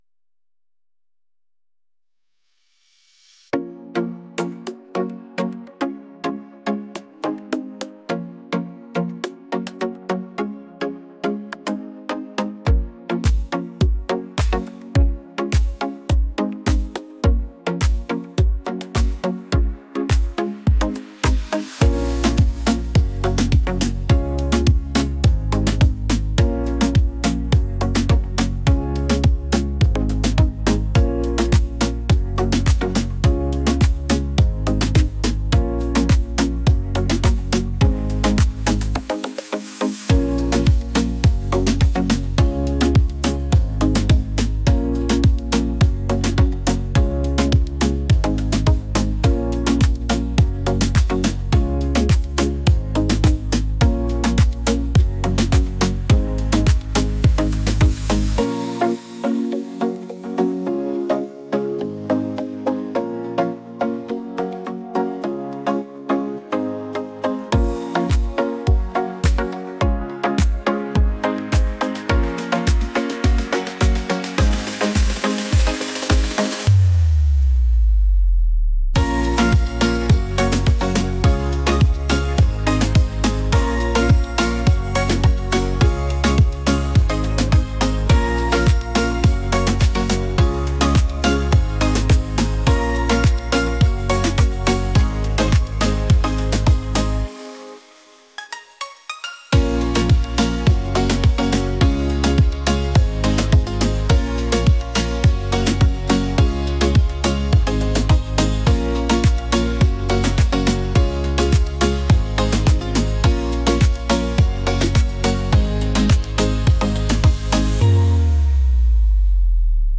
romantic | pop